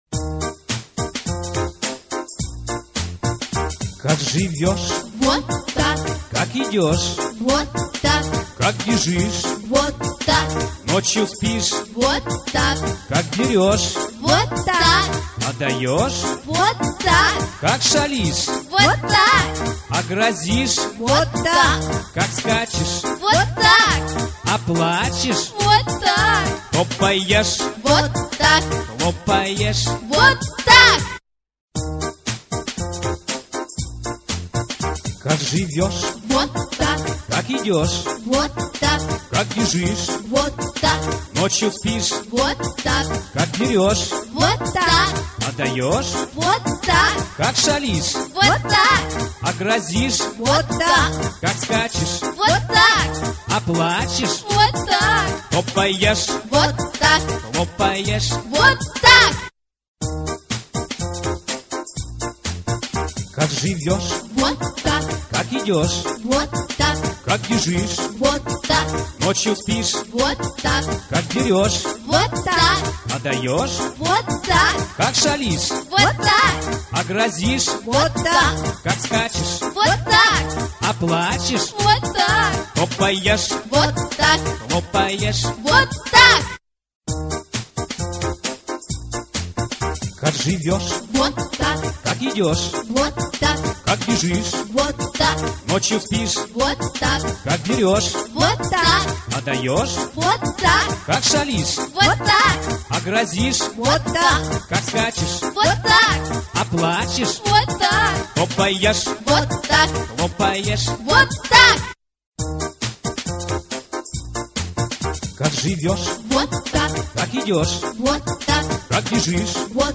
Как живешь? - песенка с движениями - слушать онлайн